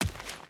Dirt Run 1.wav